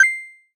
pickup_coin.ogg